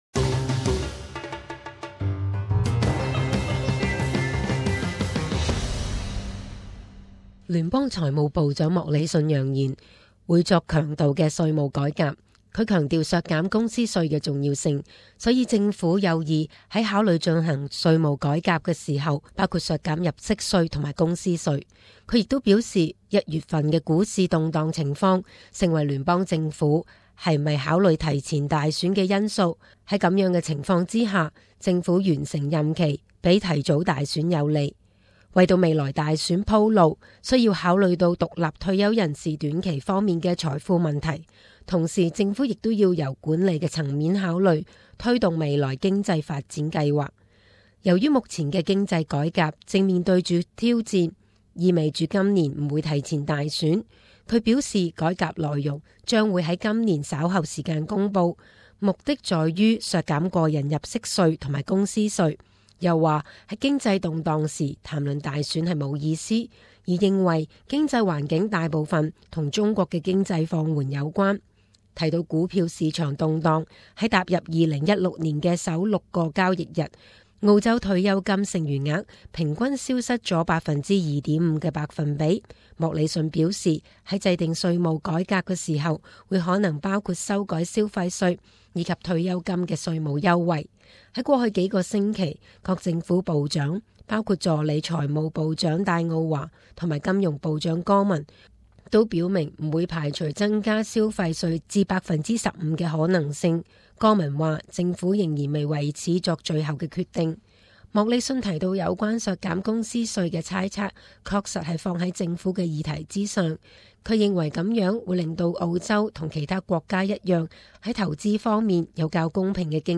时事报导：联邦财政部长莫理逊提出强度的税务改革建议